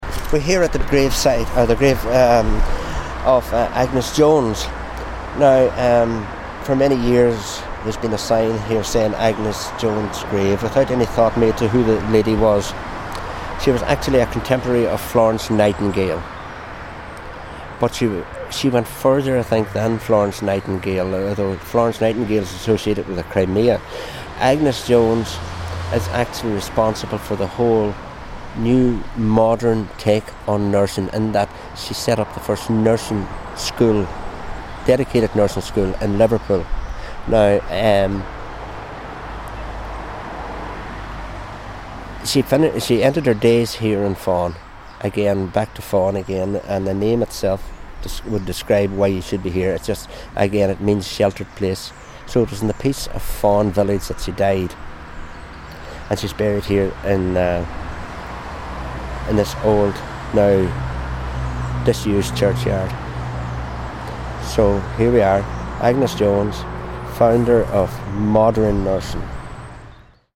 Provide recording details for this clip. out and about in Inishowen telling us about its rich past. We're in Fahan to salute the pioneering nurse, Agnes Jones, often overlooked by her more famous contemporary, Florence Nightingale.